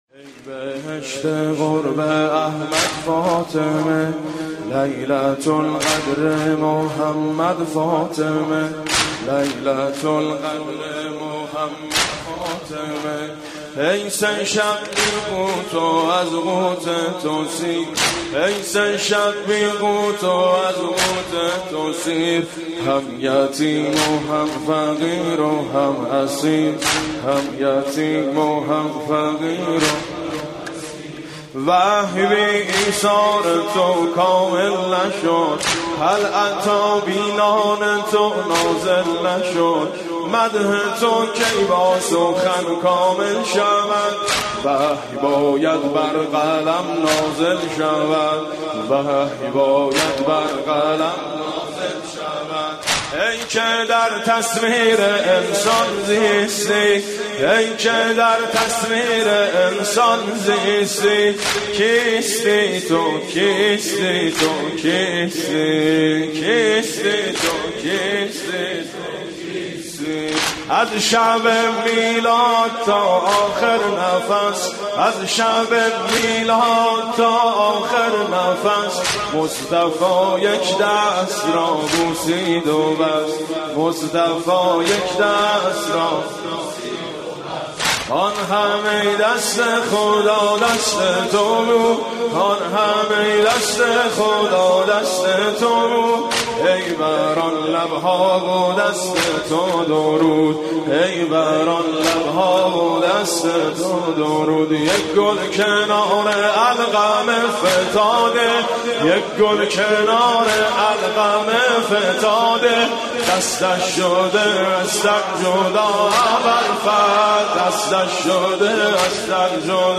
مداحی و نوحه